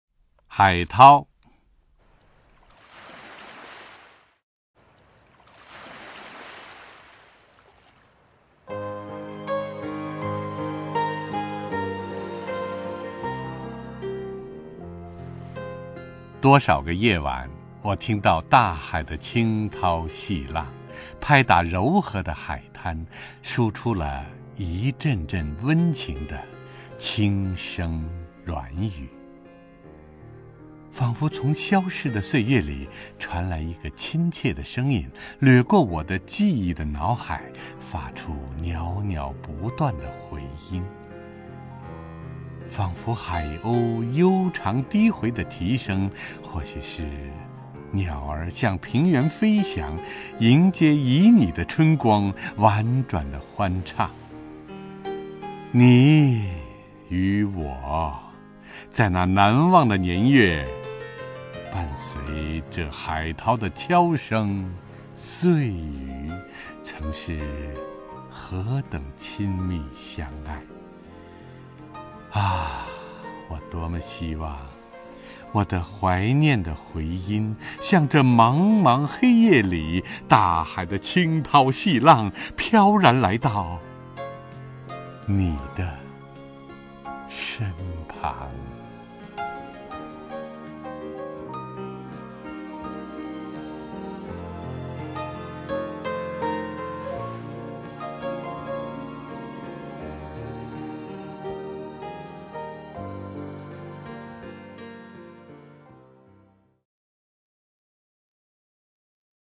首页 视听 名家朗诵欣赏 陈铎
陈铎朗诵：《海涛》(（意）萨瓦多尔·夸西莫多，译者：吕同六)